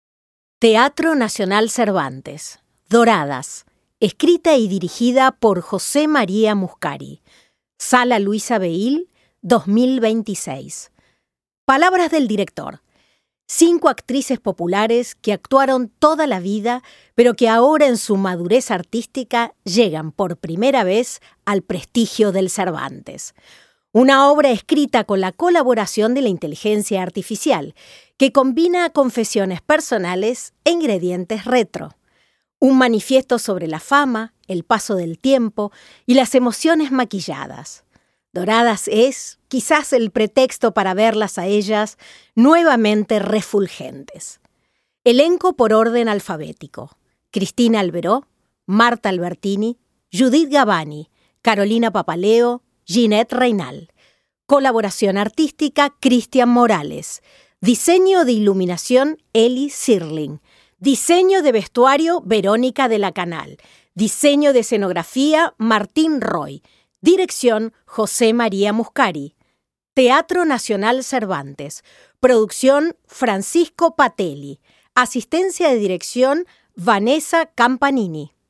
TNC Accesible | El siguiente audio corresponde a lectura del programa de mano del espectáculo Doradas en la voz de Carolina Papaleo.